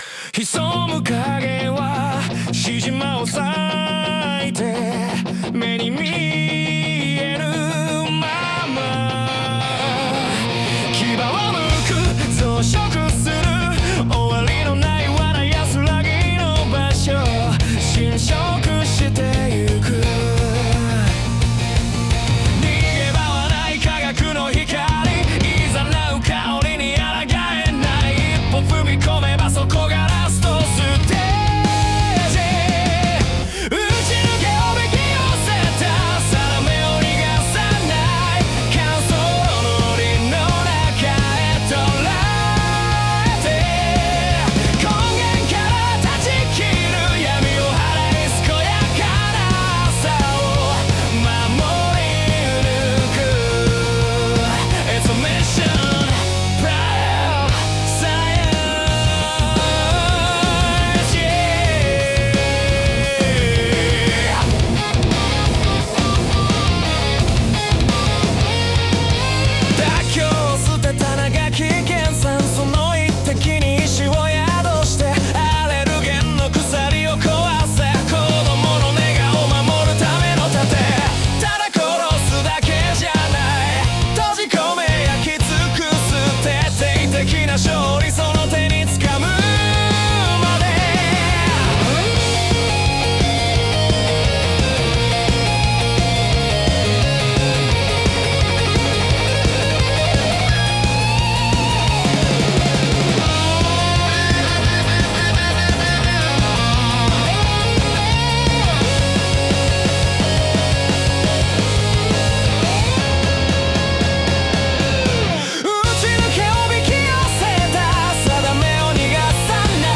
(Fast Distorted Guitar Riff) (Heavy Drum
Fast-Distorted-Guitar-Riff-Heavy-Drum.mp3